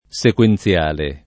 sequenziale [ S ek U en ZL# le ] agg.